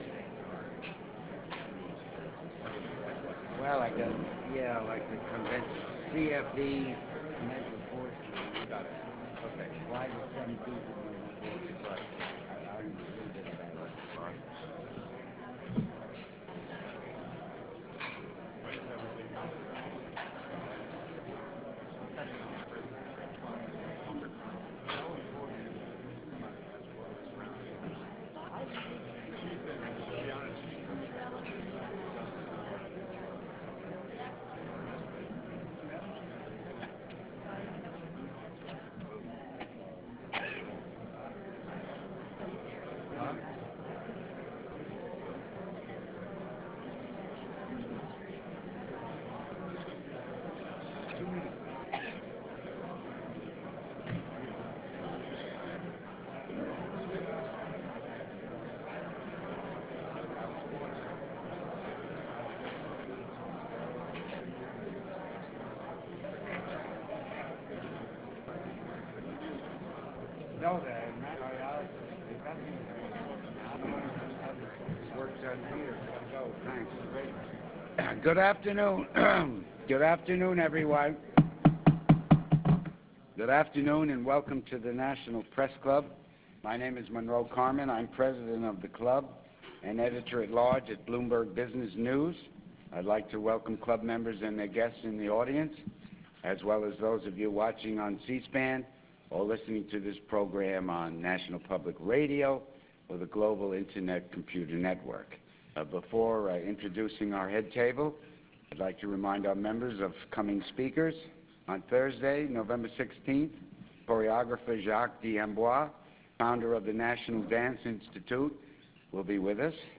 .au format (25.1 MB), .gsm format (5.2 MB), .ra format (3.1 MB) The National Press Club Luncheons are brought to you by the Internet Multicasting Service and our sponsors under an agreement with the National Press Club Board of Governors.